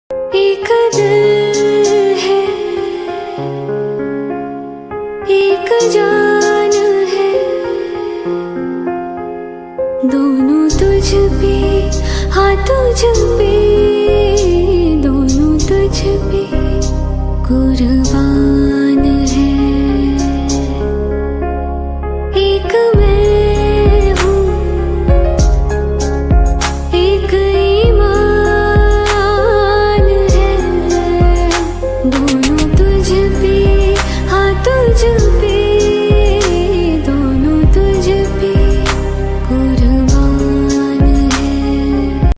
Categories Devotional Ringtones